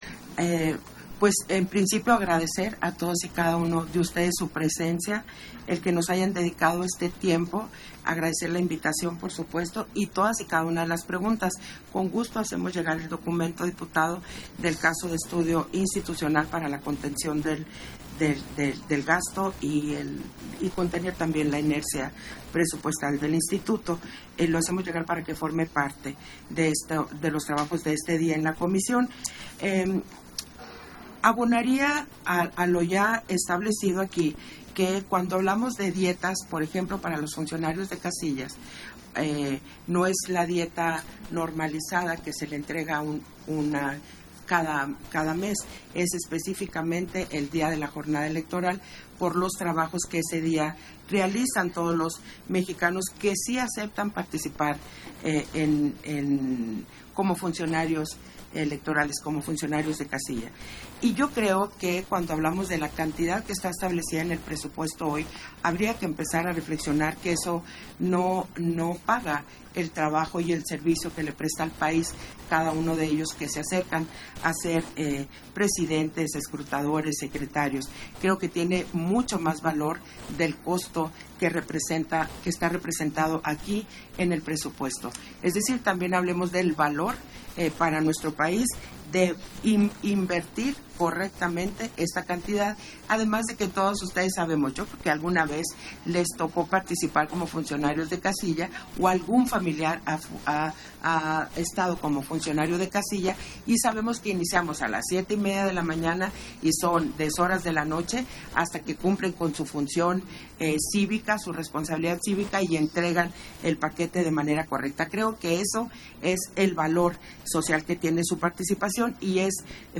Intervenciones de Guadalupe Taddei, en la reunión de trabajo que sostuvo con la Comisión de Presupuesto y Cuenta Pública de la LXV Legislatura de la Cámara de Diputados